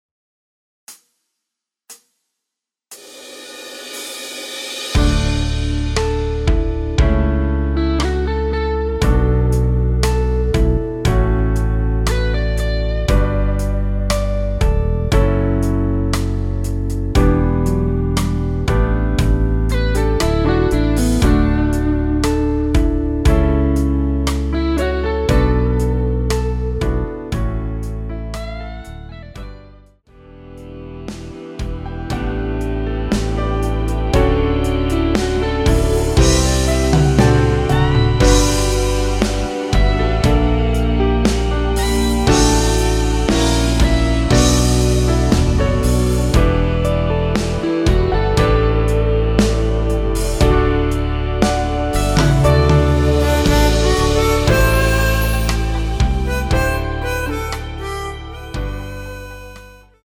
전주없이 시작하는 곡이라 카운트 4박 넣어 놓았습니다.(미리듣기 참조)
원키에서(+5)올린 MR입니다.
앞부분30초, 뒷부분30초씩 편집해서 올려 드리고 있습니다.